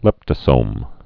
(lĕptə-sōm)